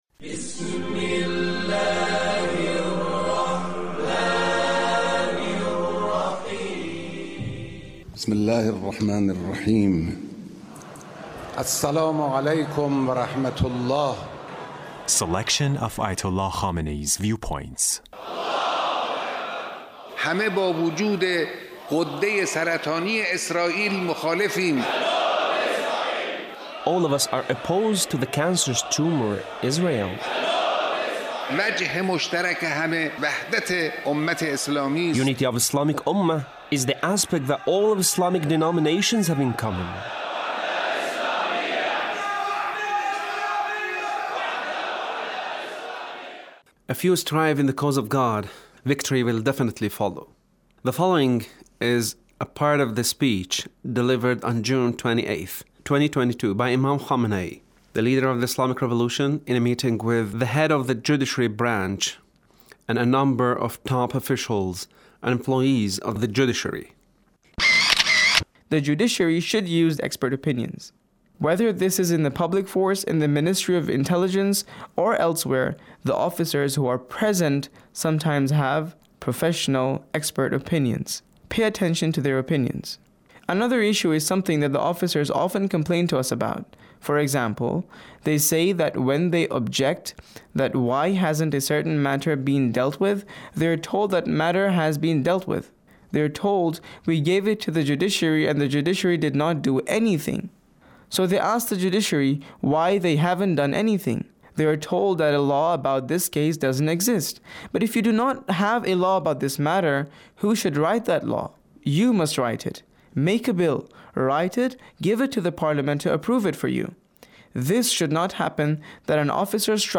Leader's Speech with Judiciary Officials